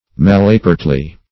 [1913 Webster] -- Mal"a*pert`ly, adv.